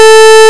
pulseOscillator.wav